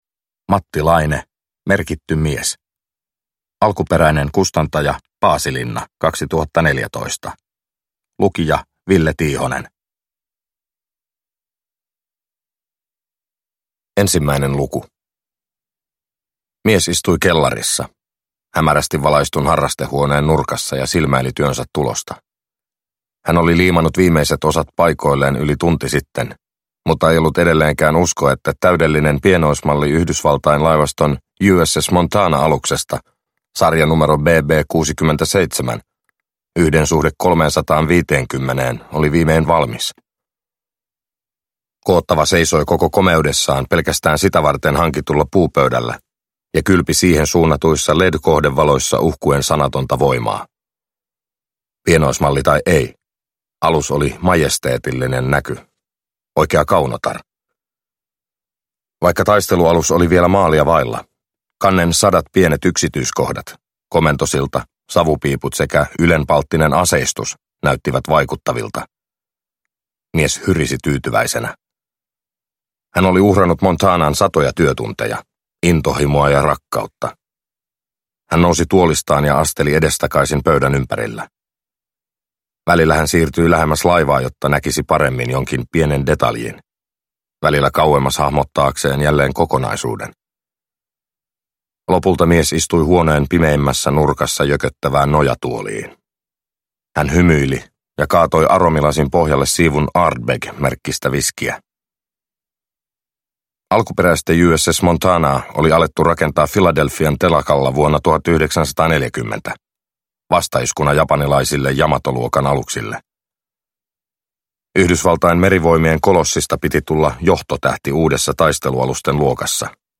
Merkitty mies – Ljudbok – Laddas ner